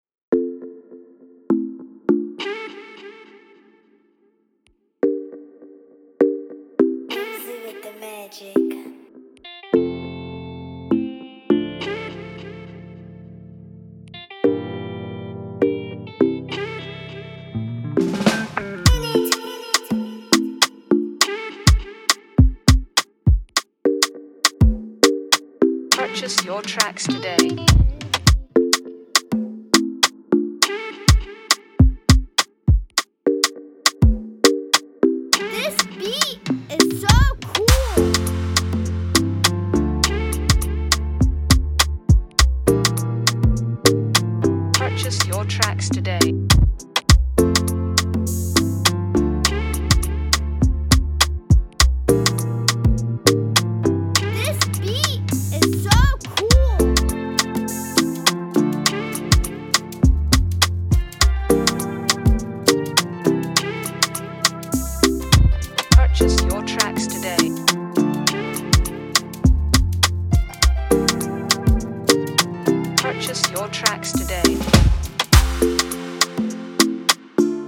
free beat